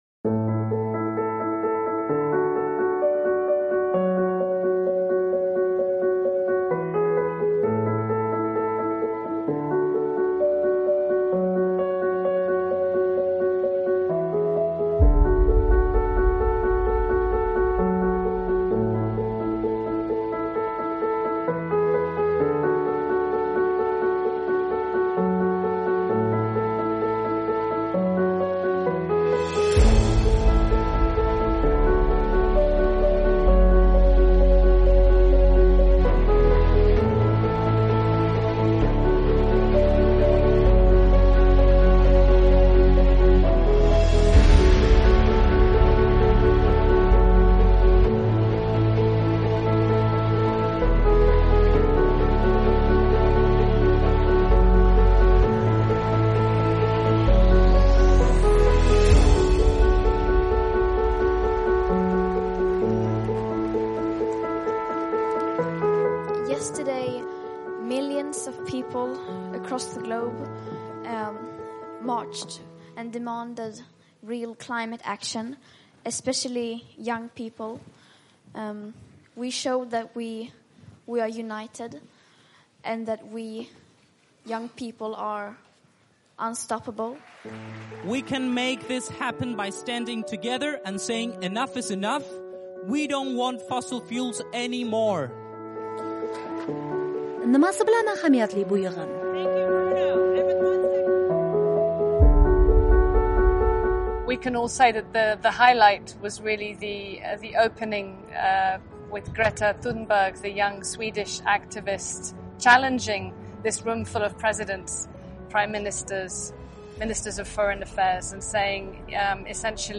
Islohotlar: BMTning O'zbekistondagi bosh vakili bilan suhbat